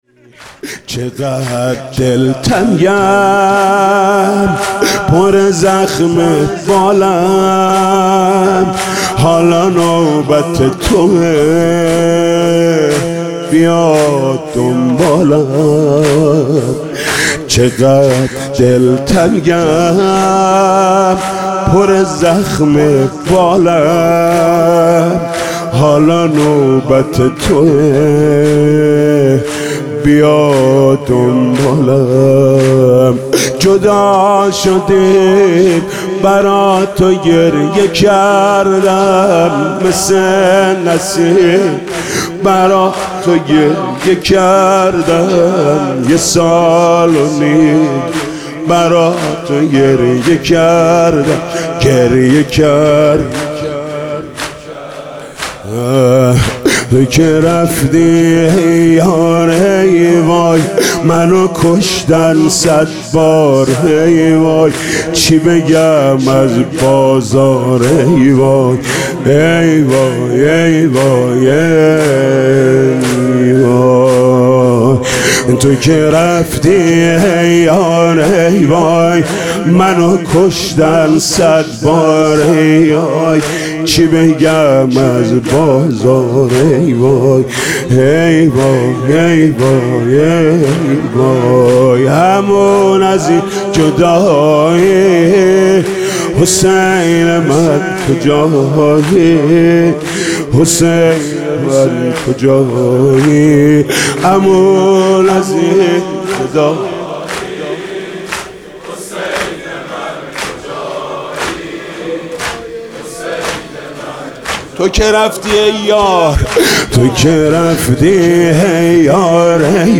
زمینه: چقدر دلتنگم پُره زخمه بالم